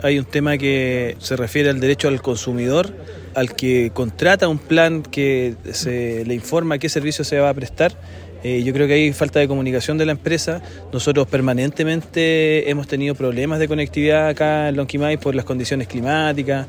El alcalde de Lonquimay, Eduardo Yáñez, descartó problemas en los servicios del municipio y señaló que el problema afectaría los derechos del consumidor.
cuna-movistar-alcalde.mp3